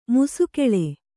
♪ musukeḷe